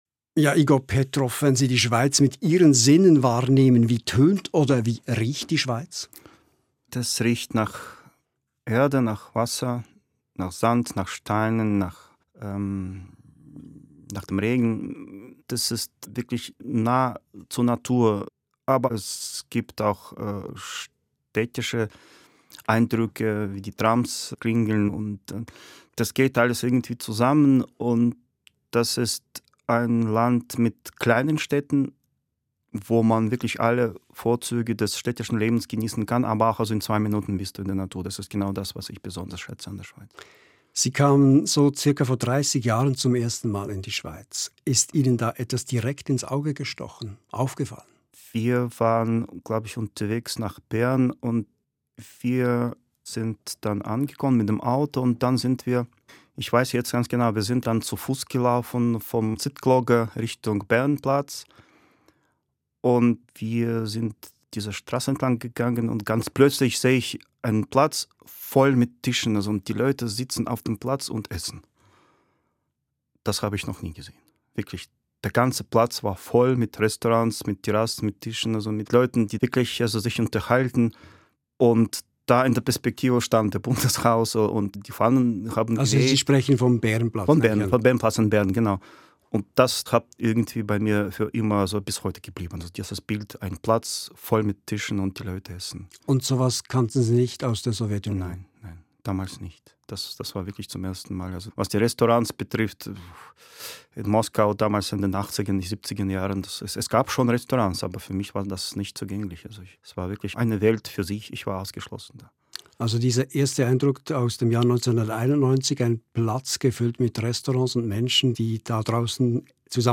Folge 4 des Gesprächs